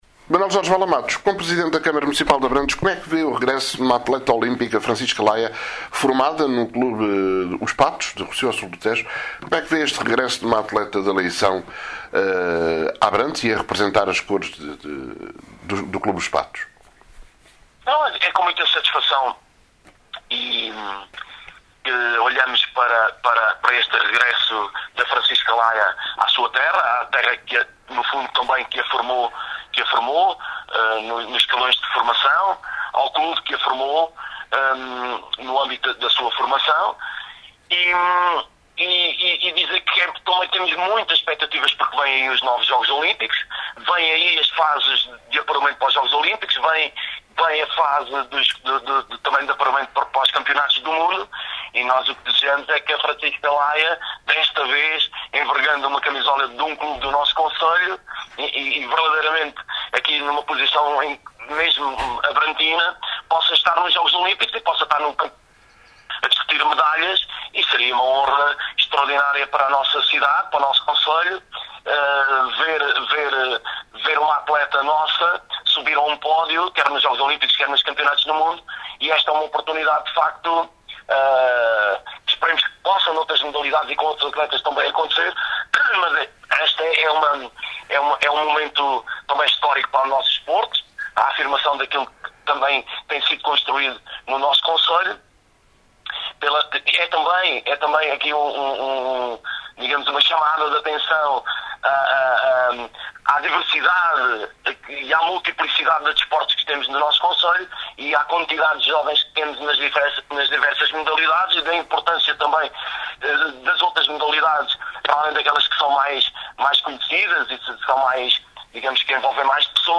ÁUDIO: MANUEL JORGE VALAMATOS, PRESIDENTE CM ABRANTES: